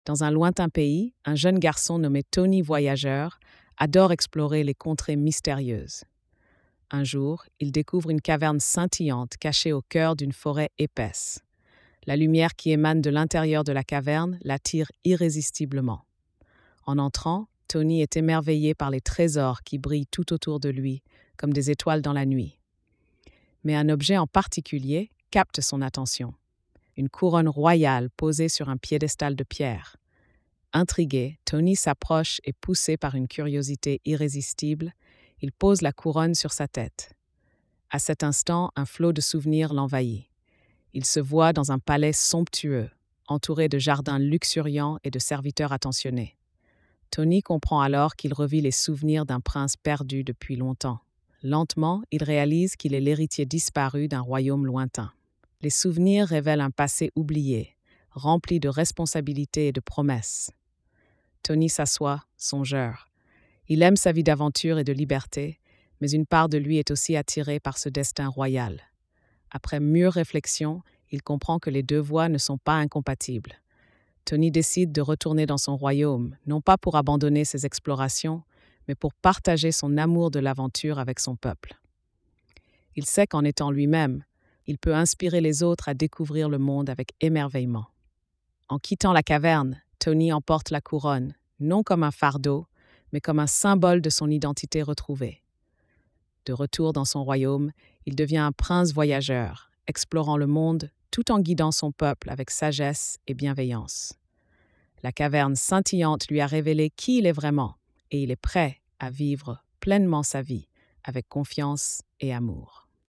Tony et la Couronne des Souvenirs - Conte de fée
🎧 Lecture audio générée par IA